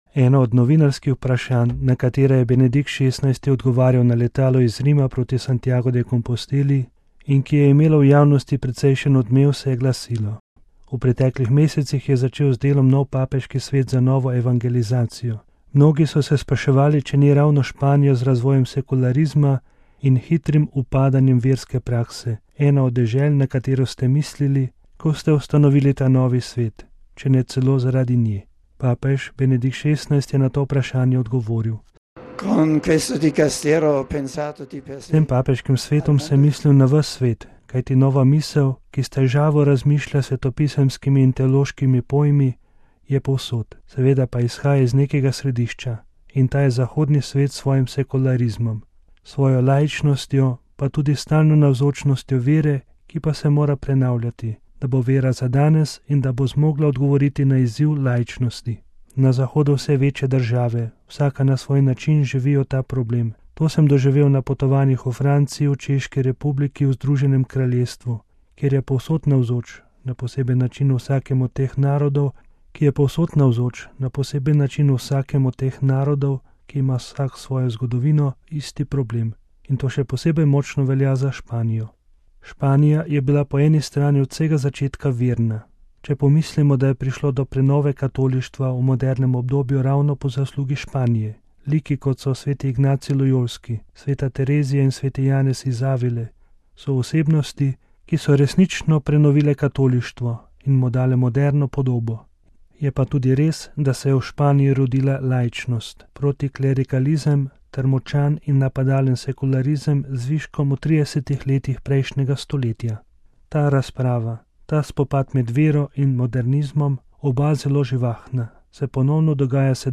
Ob papeževem obisku v Španiji se te dni pojavlja vprašanje krščanskih korenin Evrope. O začetku pobude za združeno Evropo, njenih temeljih in problemih, ki se zadnje čase pojavljajo v Sloveniji, je za naš radio spregovoril ljubljanski nadškof in metropolit Anton Stres.